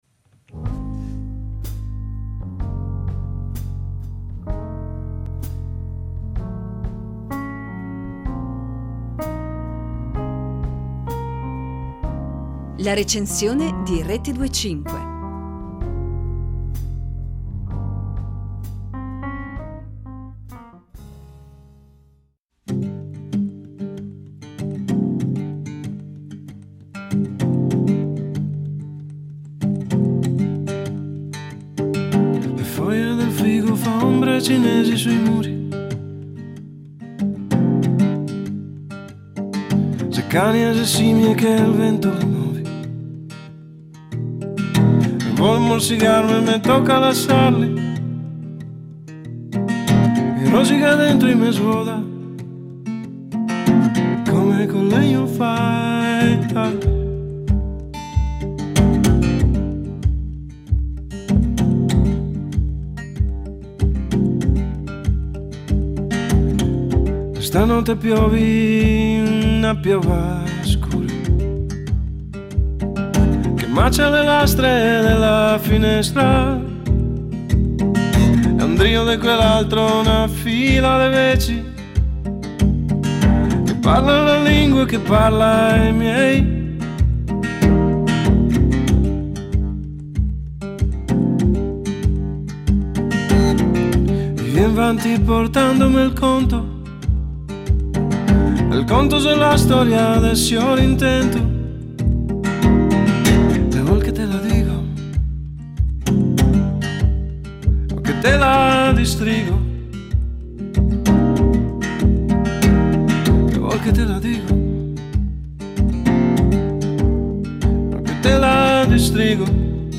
È un disco essenziale. Un disco di cantautorato folk ridotto all’osso, e, per giunta, cantato in dialetto triestino.